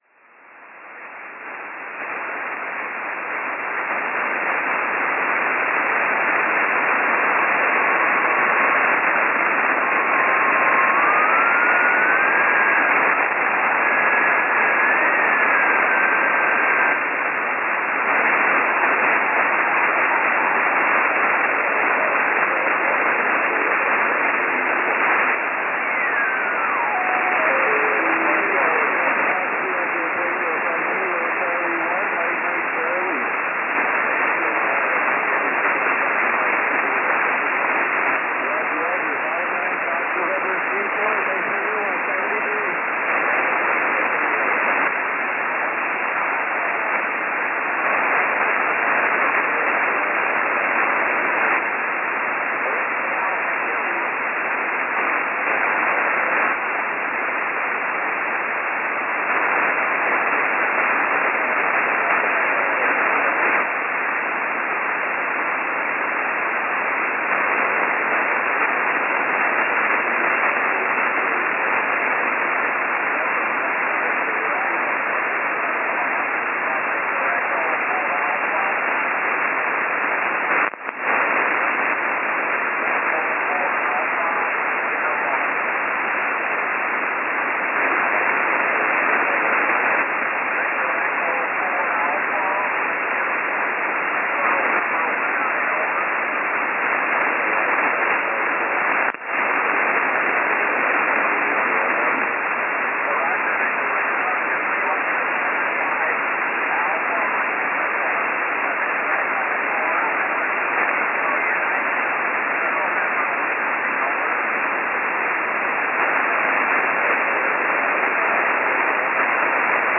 Distance: 7131.5km via RS-44. RSTr 59 / RSTs 59.
Worked with 2x IC-705 and Arrow II antenna from JO31OM (GMA DA/NW-357).
Sound recording of my RX signal: